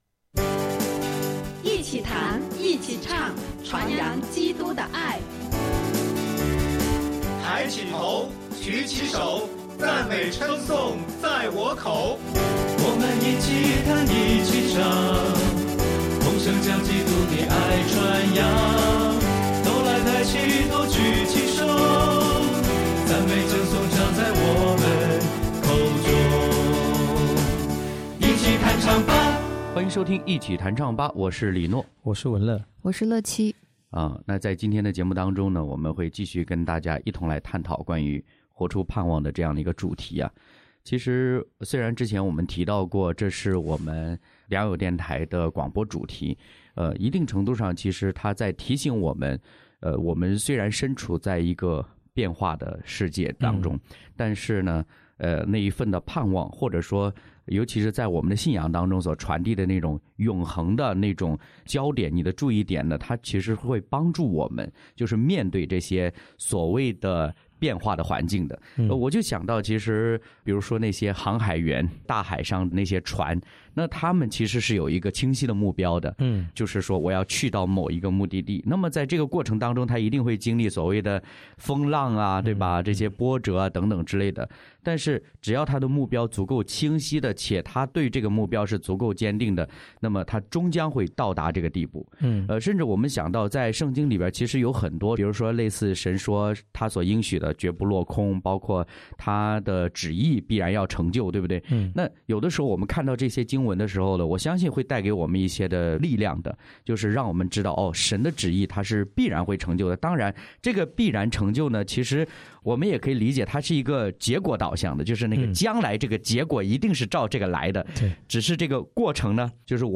敬拜分享：我要活出盼望时（8）；诗歌：《我们欢迎君王降临》、《愿你裂天而降》